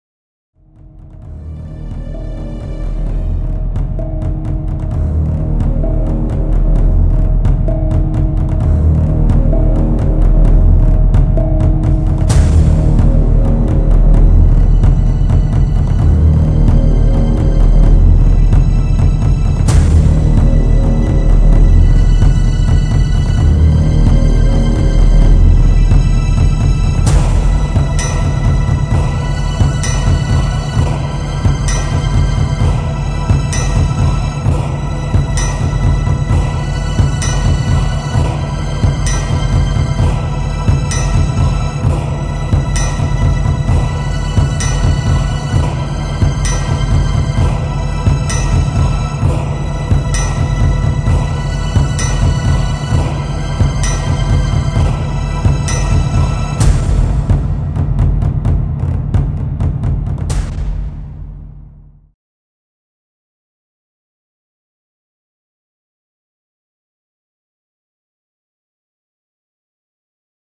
suspense tae
Cinematic-Suspense-Rising-2-Movie-Trailer-Sound-Effect.mp3